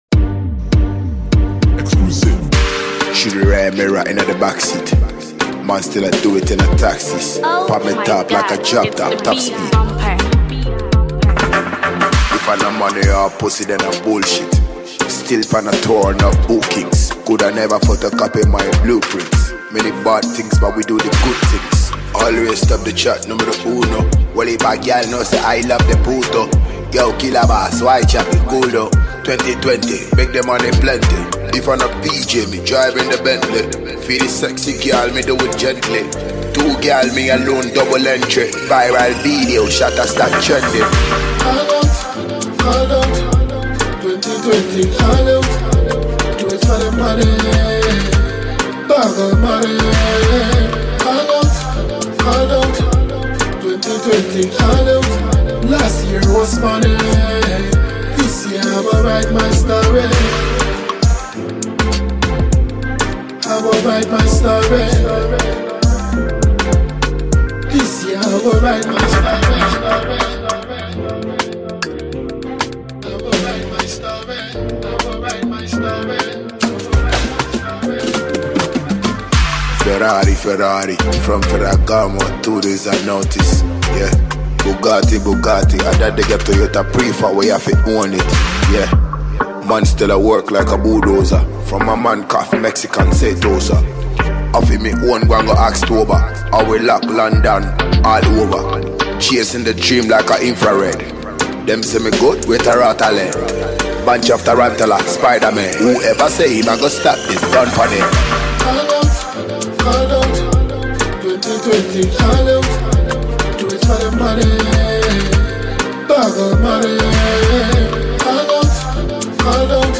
Award winning Ghanaian dancehall sensation